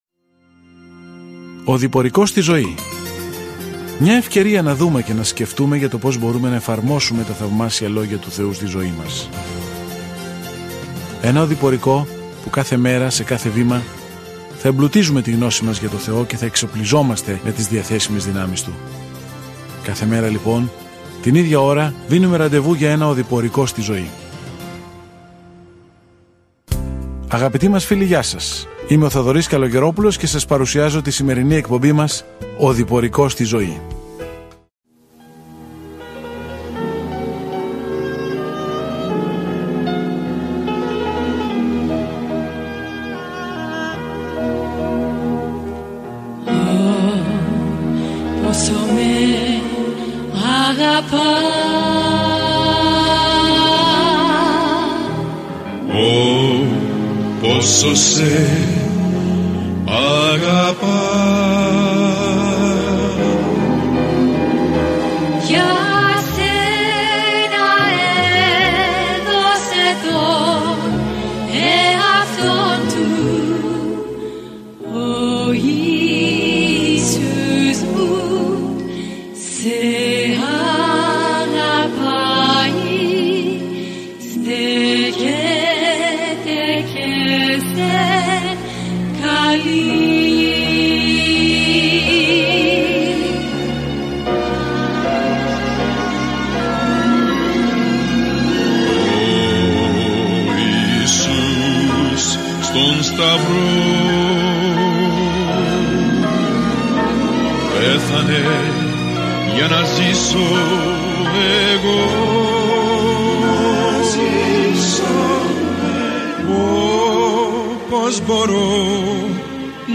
Κείμενο ΙΕΡΕΜΙΑΣ 3 Ημέρα 3 Έναρξη αυτού του σχεδίου Ημέρα 5 Σχετικά με αυτό το σχέδιο Ο Θεός επέλεξε τον Ιερεμία, έναν τρυφερό άνθρωπο, για να μεταδώσει ένα σκληρό μήνυμα, αλλά οι άνθρωποι δεν λαμβάνουν καλά το μήνυμα. Καθημερινά ταξιδεύετε στον Ιερεμία καθώς ακούτε την ηχητική μελέτη και διαβάζετε επιλεγμένους στίχους από τον λόγο του Θεού.